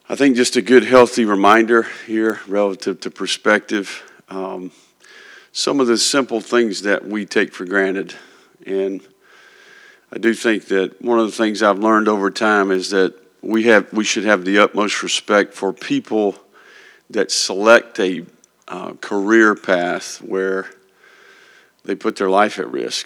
On the 22nd anniversary of the attacks on American soil, Napier began the press conference by reflecting on the tragedy.
billy-napier-raw-9-11.wav